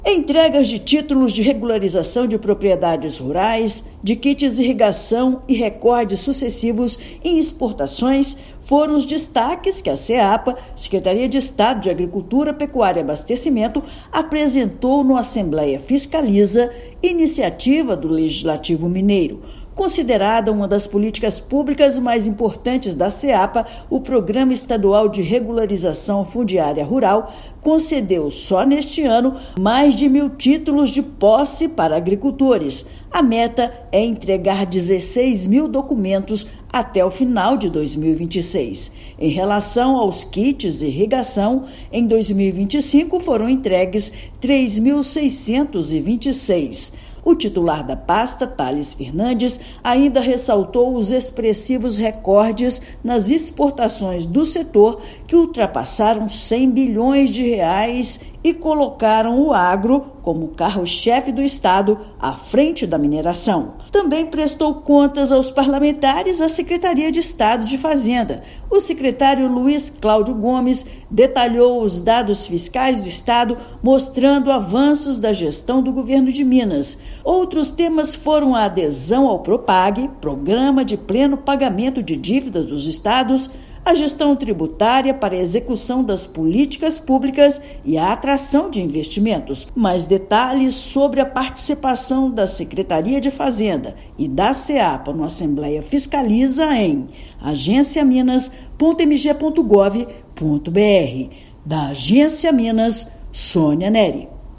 Secretarias de Agricultura, Pecuária e Abastecimento e Secretaria de Fazenda prestaram contas do trabalho desenvolvido em apresentação aos deputados estaduais. Ouça matéria de rádio.